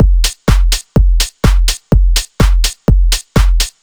• house - techno beat passage - Fm - 125 - 2.wav
A loop that can help you boost your production workflow, nicely arranged electronic percussion, ready to utilize and royalty free.
house_-_techno_beat_passage_-_Fm_-_125_-_2_k3j.wav